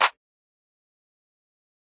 GOING DOWN CLAP.wav